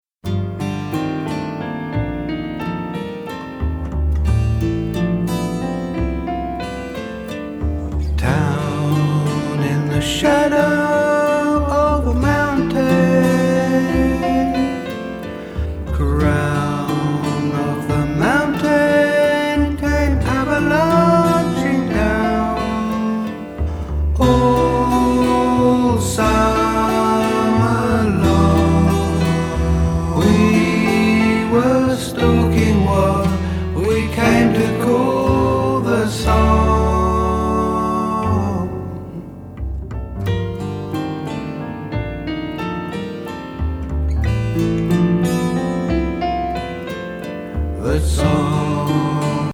ひんやり憂いボーカルが素晴らしい